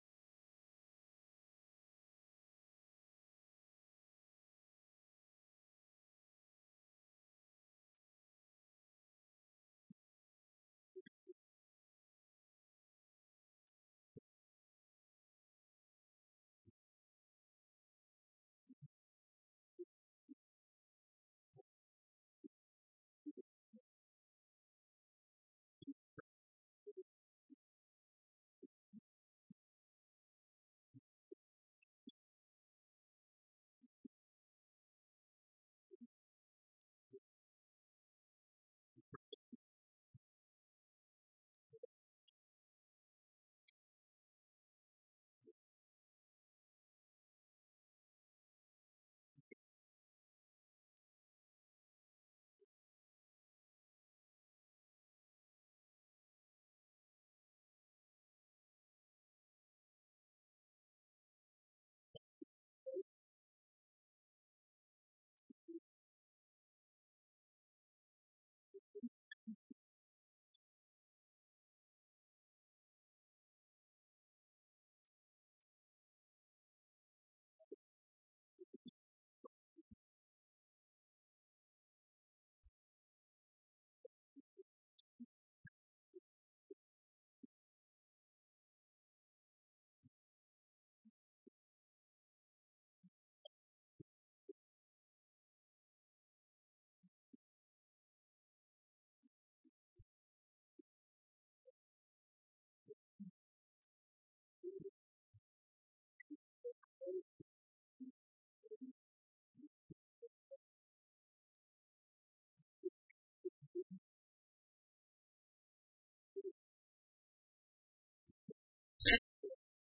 Passage: Matthew 26:46-50a Service Type: Sunday
Sermon Only